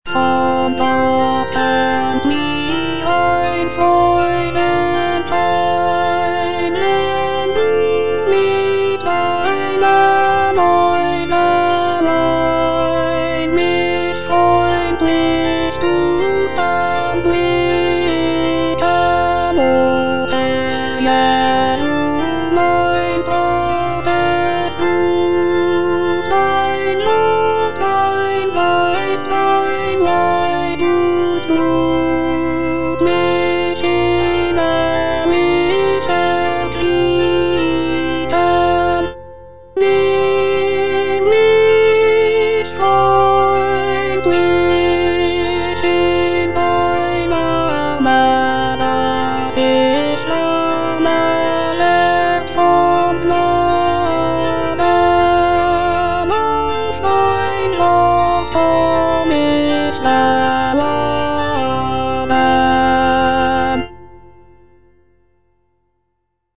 Choralplayer playing Cantata
J.S. BACH - CANTATA "ERSCHALLET, IHR LIEDER" BWV172 (EDITION 2) Von Gott kömmt mir ein Freudenschein - Alto (Voice with metronome) Ads stop: auto-stop
The music is characterized by its lively rhythms, rich harmonies, and intricate counterpoint.